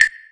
Perc 4.wav